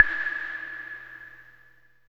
12 CLAVE  -R.wav